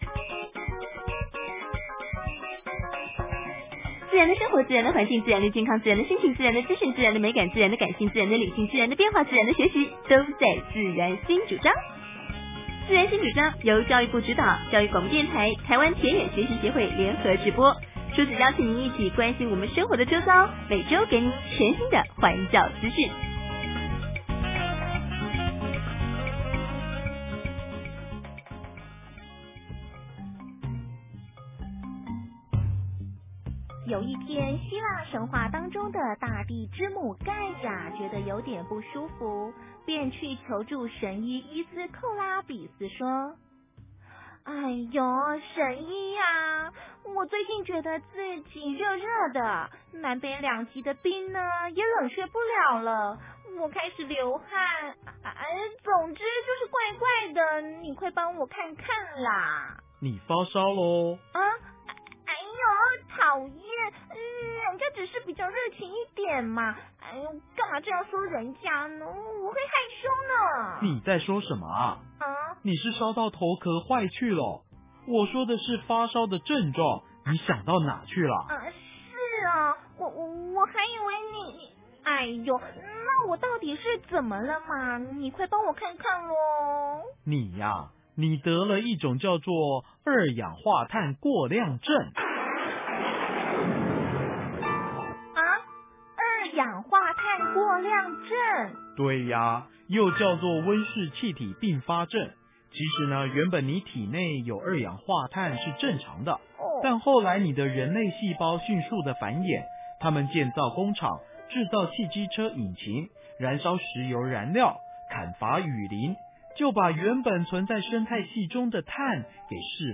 環境教育廣播劇 網上搶先聽！ - 環境資訊中心